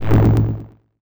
whoom.wav